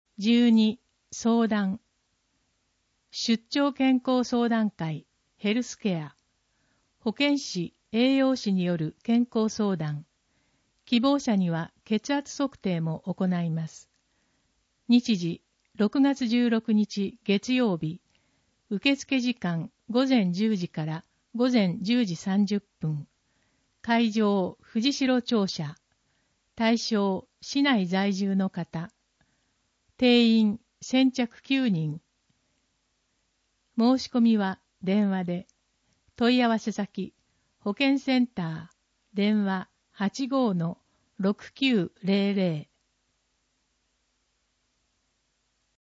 取手市の市報「広報とりで」2025年6月1日号の内容を音声で聞くことができます。音声データは市内のボランティア団体、取手朗読奉仕会「ぶんぶん」の皆さんのご協力により作成しています。